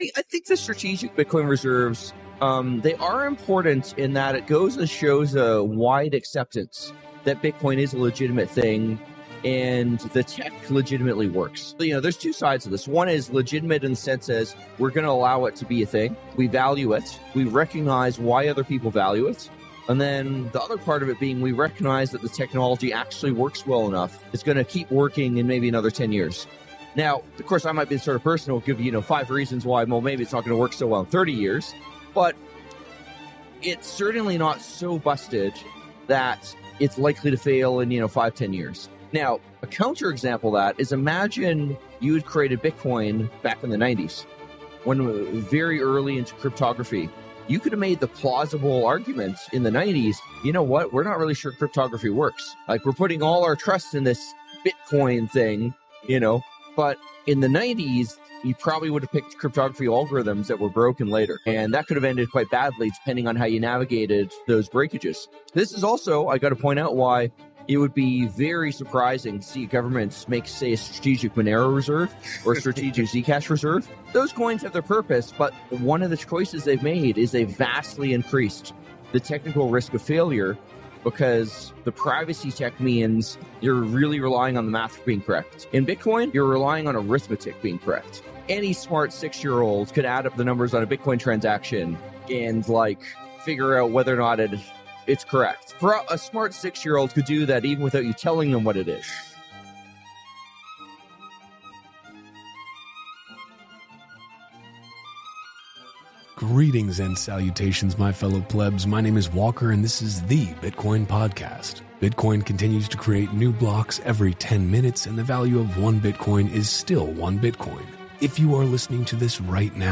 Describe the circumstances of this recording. In this live stream from the Rumble booth in Vegas